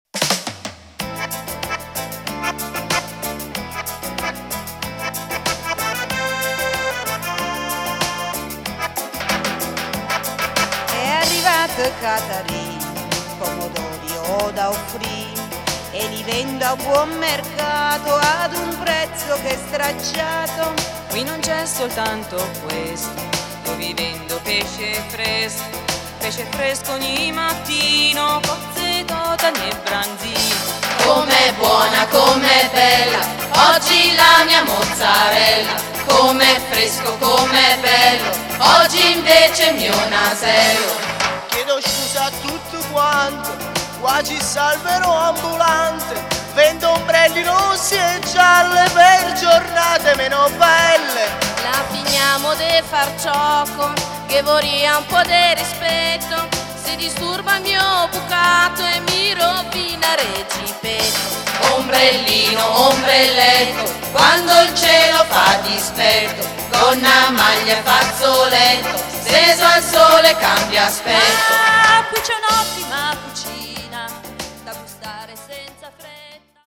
Trovate in questa sezione alcuni dei brani cantati durante lo spettacolo.